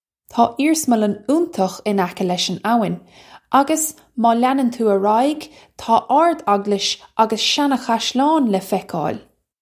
Pronunciation for how to say
Taw ear-smullun oontukh in ekka lesh un ow-in, uggus maw lyanun too urr eye-g taw ard-og-lish uggus shana-khash-lawn luh feck-oil.
This is an approximate phonetic pronunciation of the phrase.